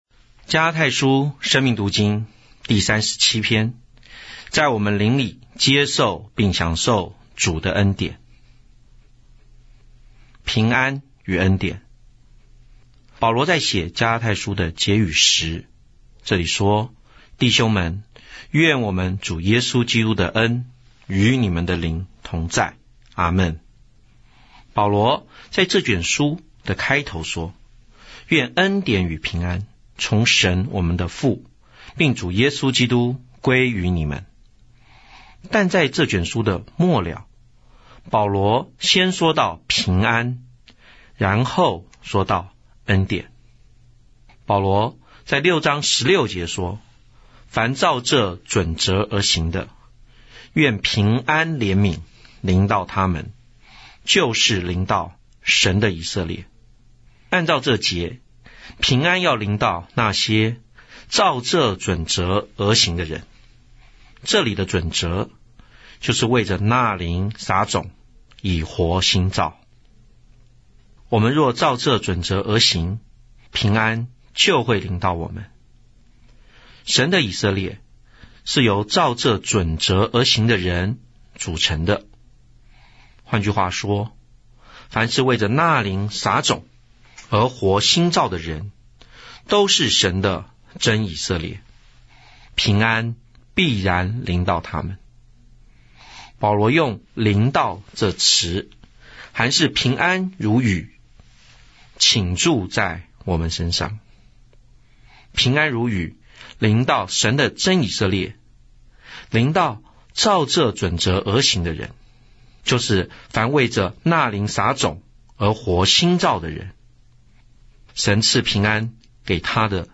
追求日期 12/8/2025👆加拉太書生命讀經 第三十七篇全篇👆延伸讀經段落及註解：加六18註🔉語音播放生命讀經📃新約聖經恢復本(紙本)：P812~P813📃生命讀經(紙本)：P396~P399L10